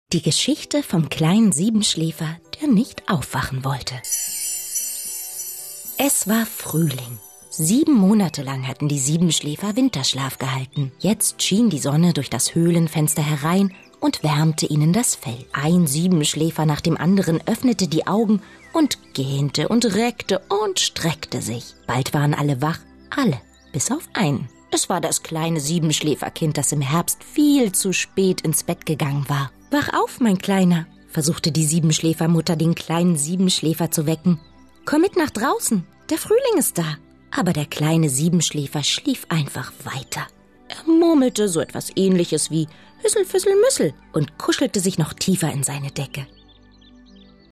Meine Stimme ist frisch und jung. Seriös und warm. Und manchmal lasziv.
Sprechprobe: Industrie (Muttersprache):
My voice is fresh and young. Serious and warm. And sometimes lascivious.
Hörbuch Die Geschichte vom kleinen Siebenschläfer.MP3